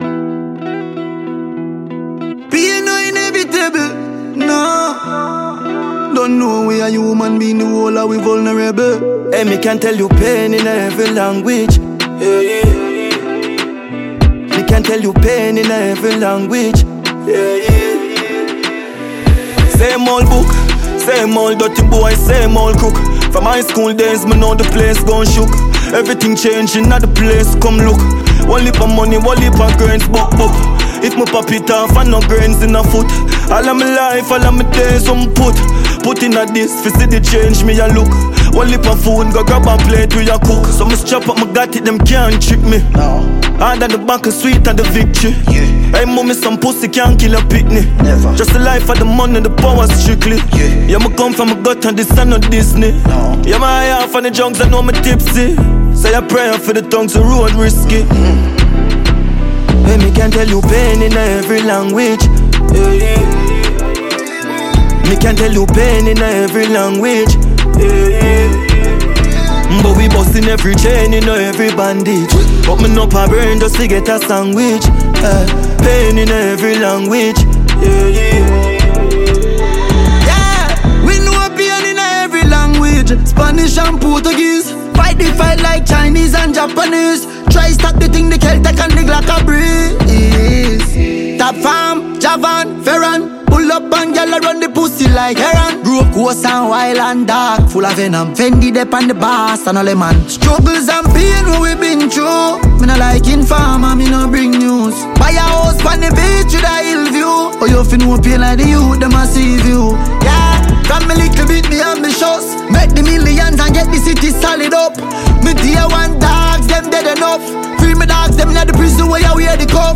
Dancehall Music